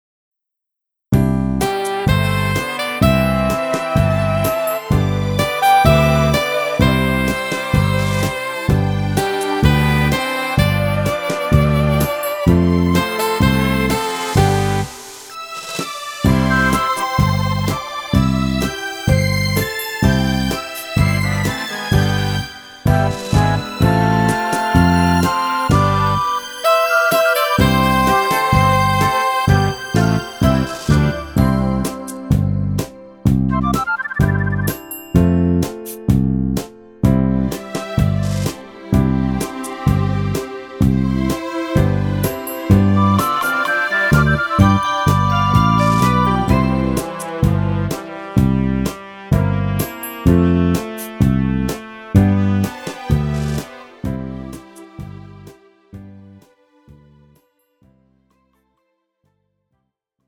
음정 원키 2:53
장르 가요 구분 Pro MR